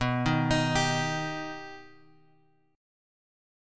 B+ chord {x 2 1 x 4 3} chord
B-Augmented-B-x,2,1,x,4,3-8.m4a